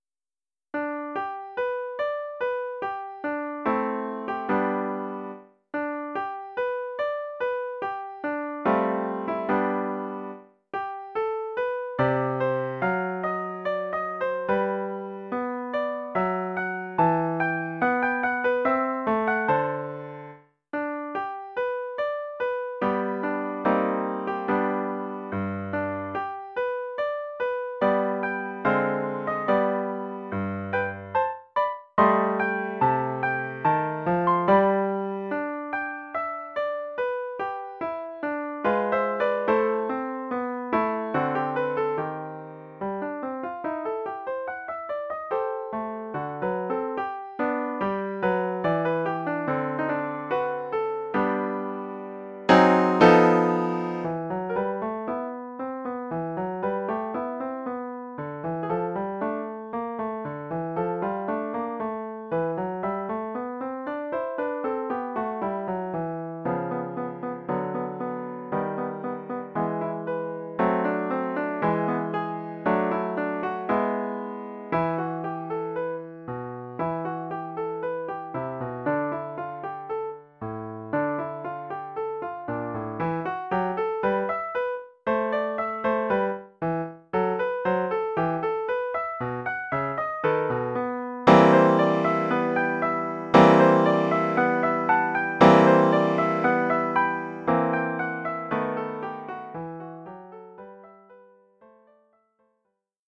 Collection : Piano
Oeuvre pour piano solo.